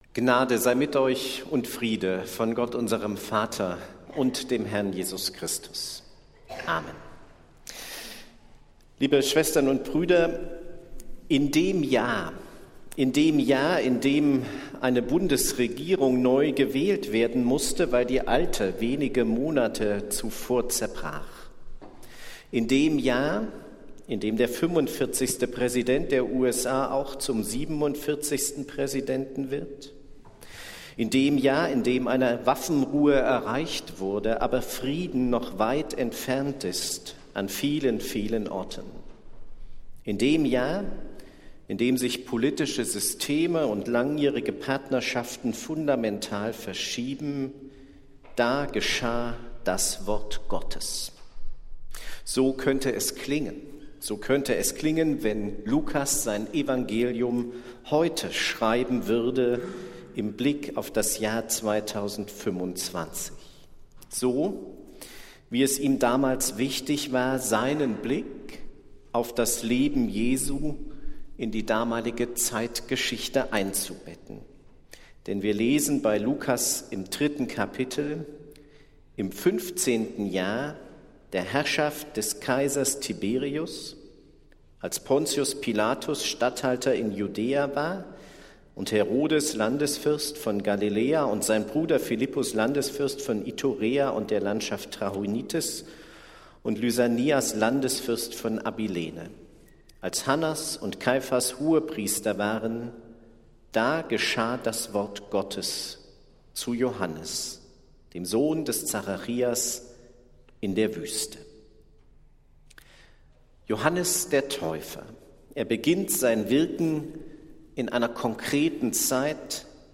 Predigt des Gottesdienstes aus der Zionskirche am Sonntag, den 14. Dezember 2025